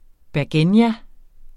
Udtale [ bæɐ̯ˈgeˀnja ]